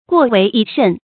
过为已甚 guò wéi yǐ shèn
过为已甚发音